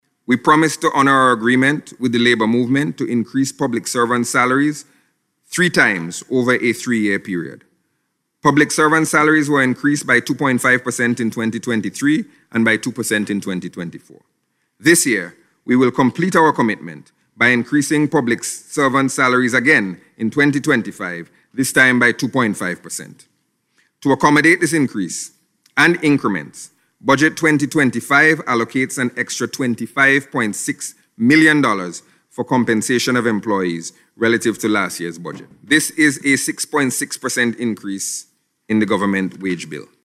Minister of Finance Camillo Gonsalves made this statement during presentation of the National Budget Address.